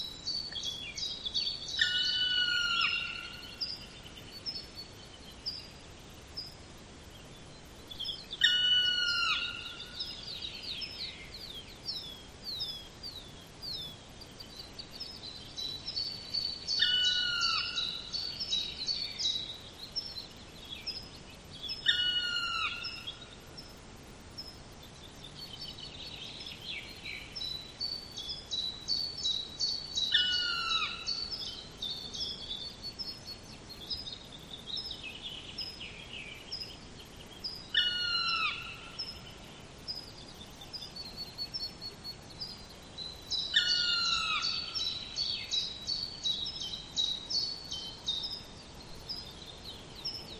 Black Woodpecker, Dryocopus martius
Ziņotāja saglabāts vietas nosaukumsValkas pagasta mežs.
Notesnebūdams lietpratējs, varbūt pārprasta kliedzējbalss atbilstība Melnajau Dzilnai